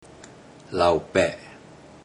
Click each Romanised Teochew word to listen to how the Teochew word is pronounced.
lao20ped0